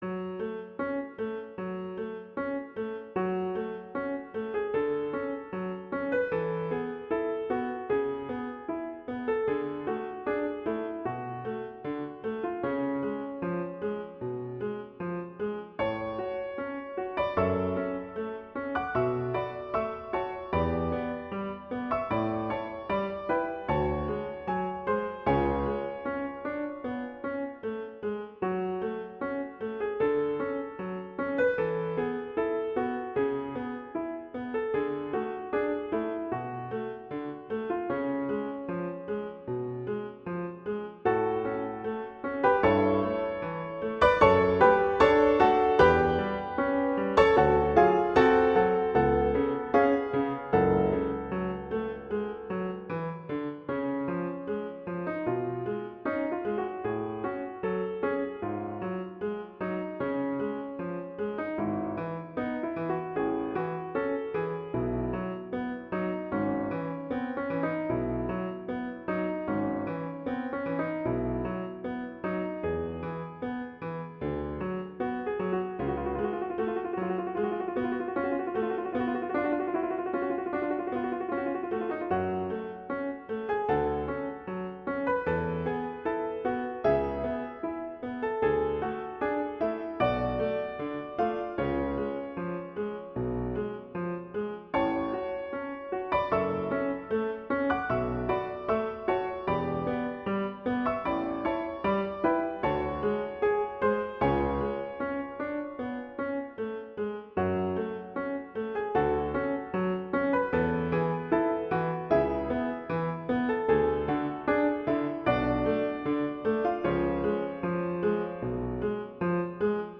classical, french
F# minor
♩=76 BPM